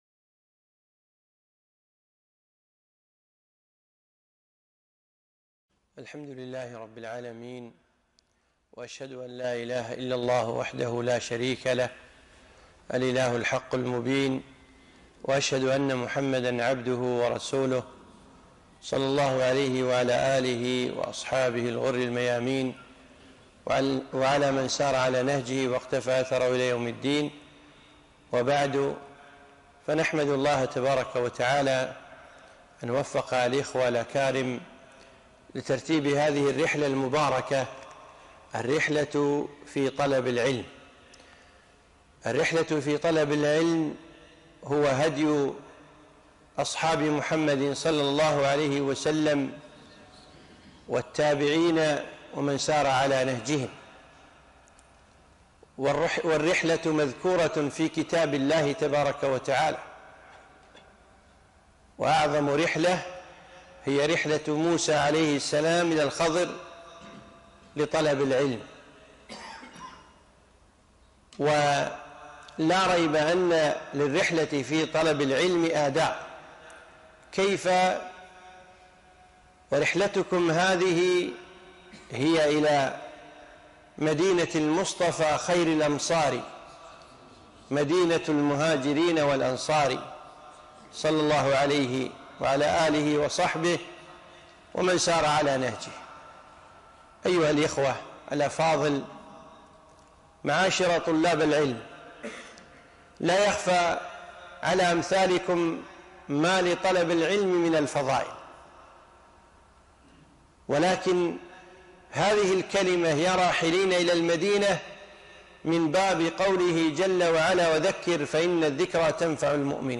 محاضرة - يا راحلين إلى المدينة - لطلاب العلم المتوجهين إلى برنامج مهمات العلم